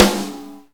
drum-hitclap.ogg